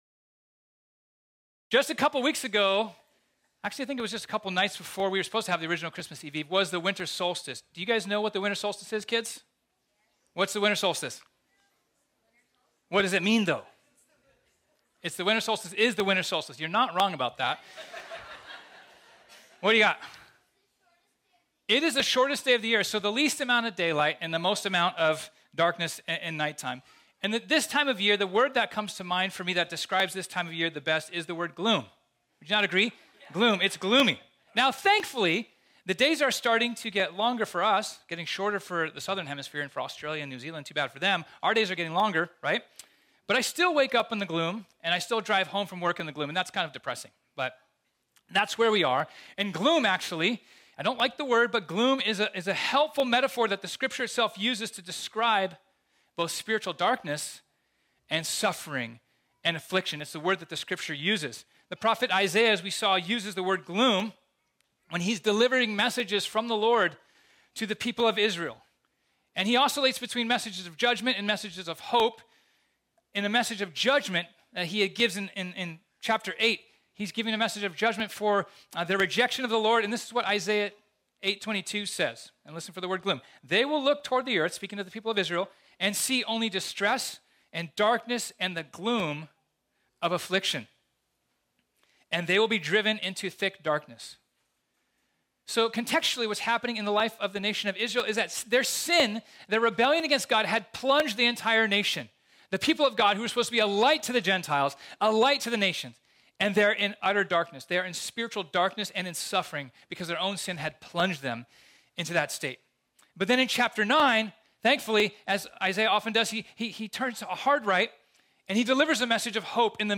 This sermon was originally preached on Friday, January 6, 2023 .